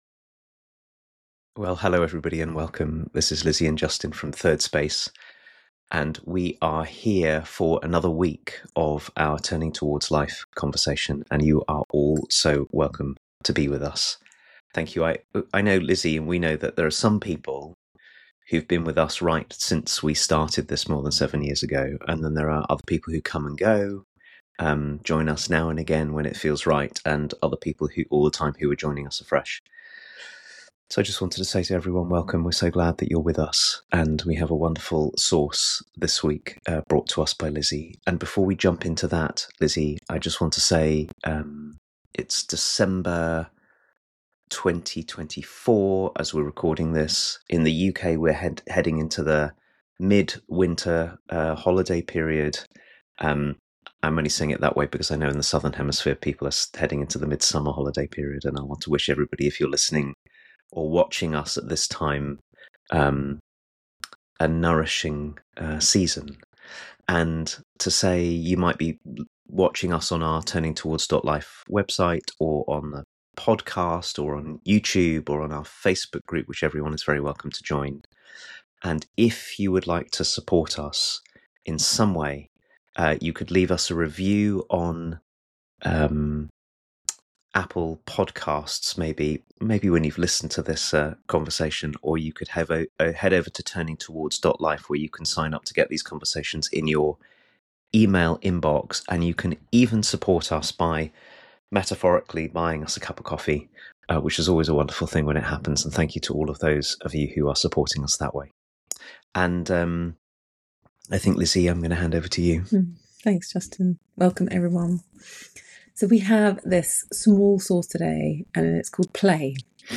weekly conversations